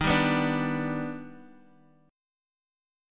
D#+M7 Chord
Listen to D#+M7 strummed